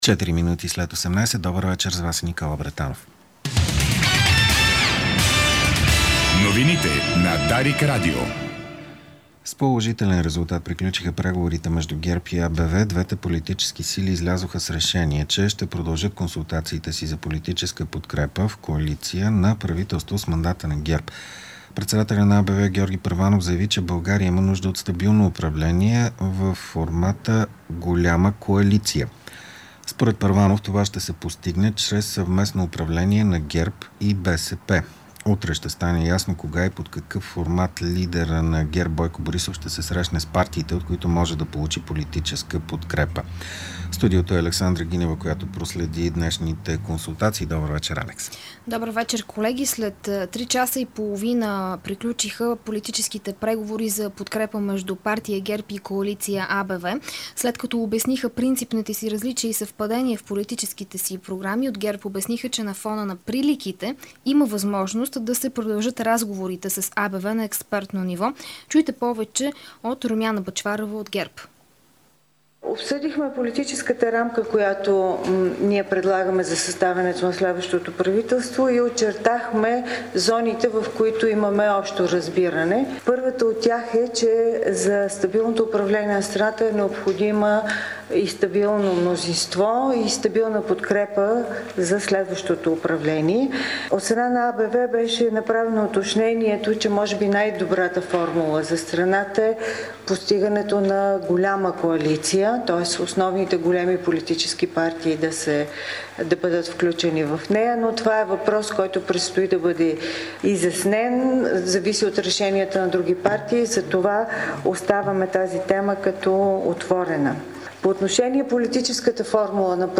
Обзорна информационна емисия